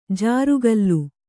♪ jārugallu